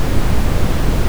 thruster.wav